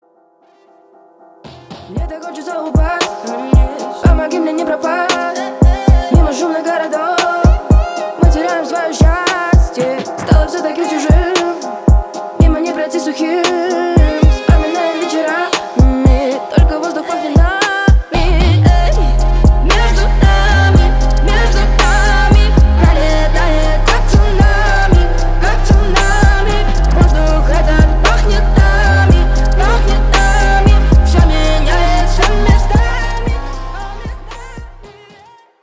женский голос
Bass